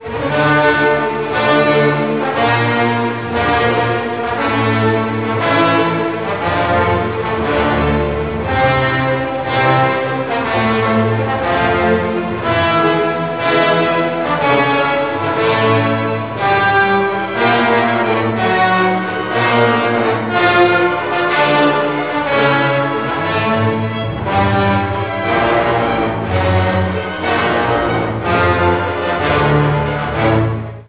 symphonic poem